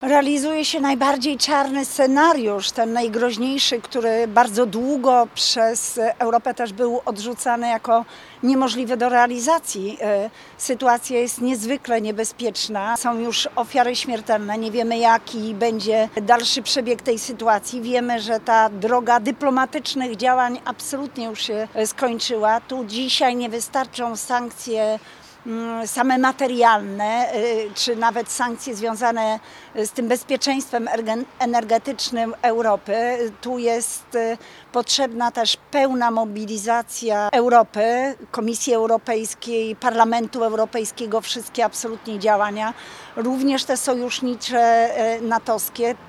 – Potrzebujemy pełnej mobilizacji Europy – mówi europoseł: